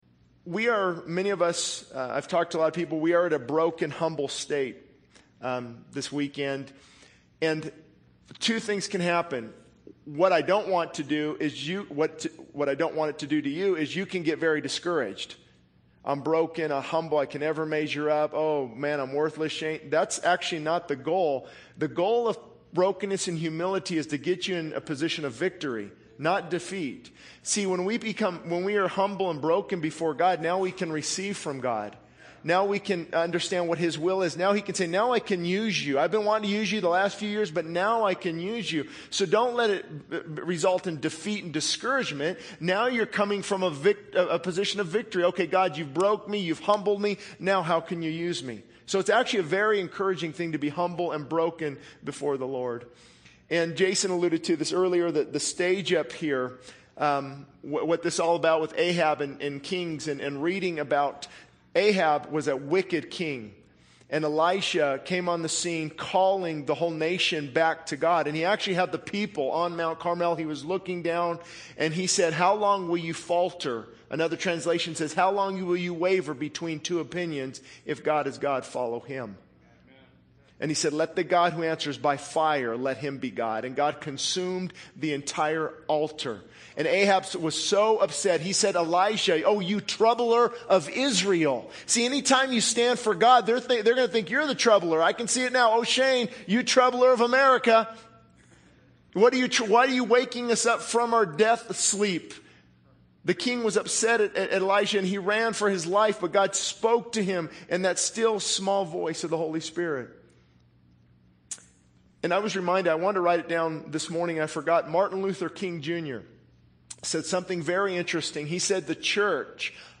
This sermon emphasizes the importance of brokenness and humility before God, highlighting that these states lead to victory, not defeat. It calls for a return to God, repentance, and revival, stressing the need for genuine relationship with Jesus over mere religion. The message challenges unbelievers, prodigals, and Pharisees to repent, seek the Holy Spirit's power, and come back to God.